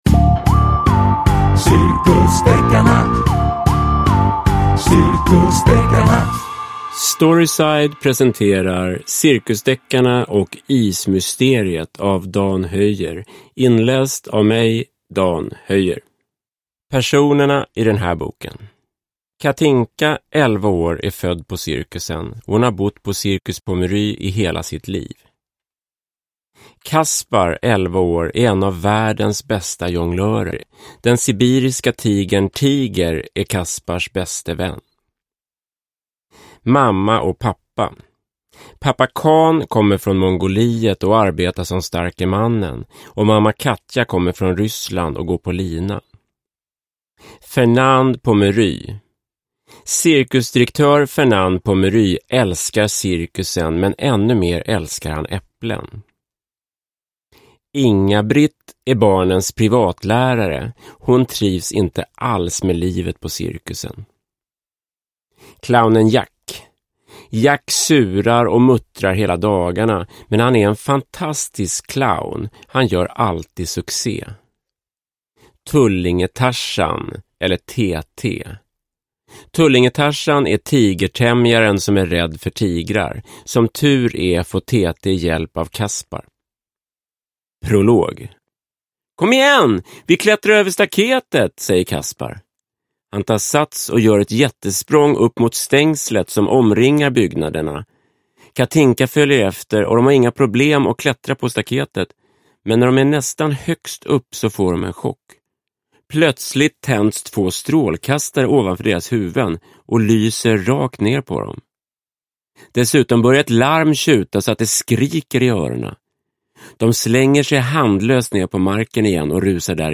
Cirkusdeckarna och ismysteriet – Ljudbok – Laddas ner